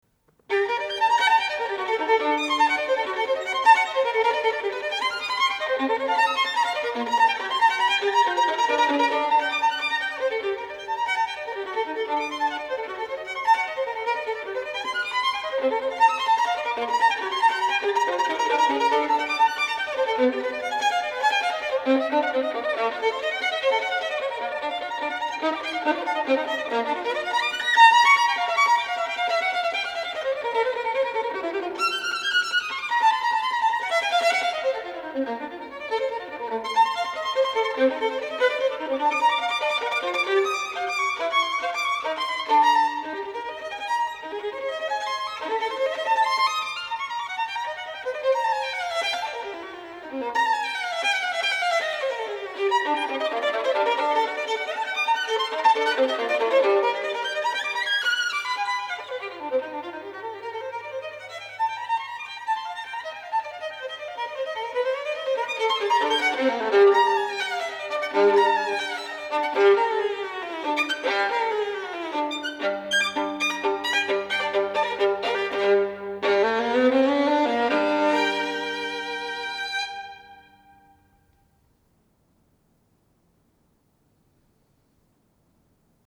для скрипки соло